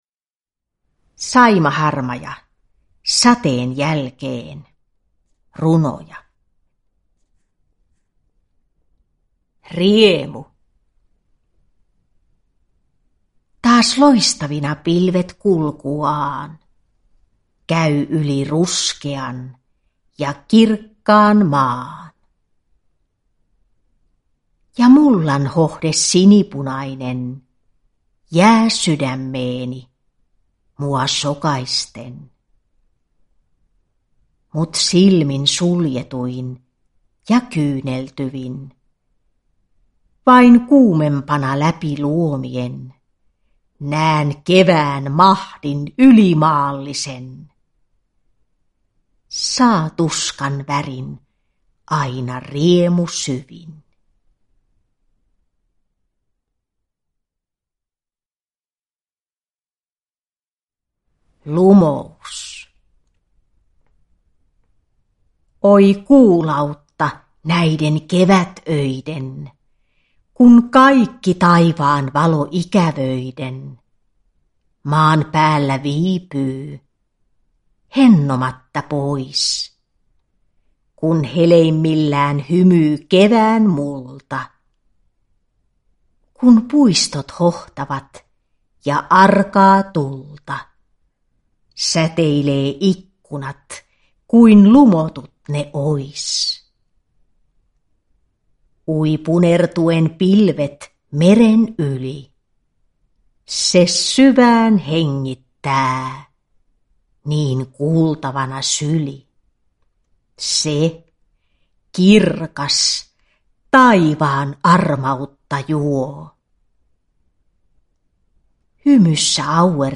Sateen jälkeen (ljudbok) av Saima Harmaja